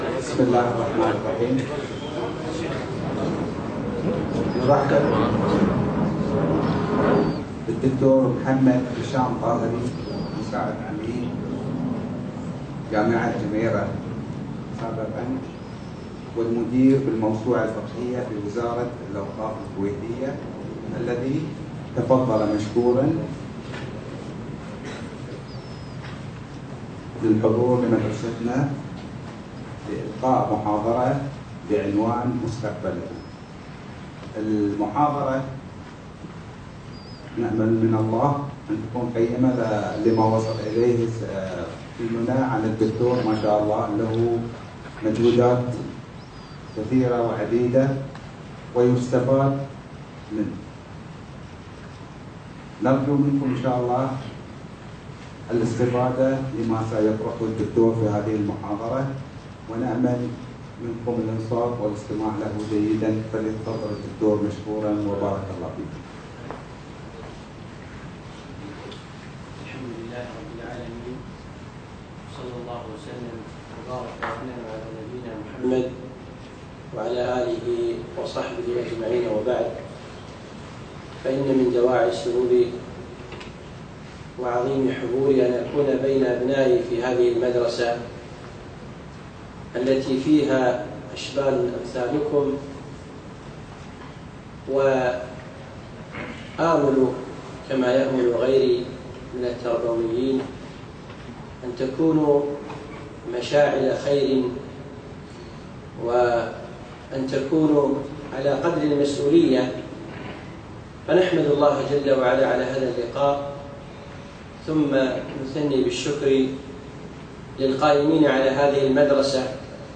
محاضرة مستقبلنا - في دولة قطر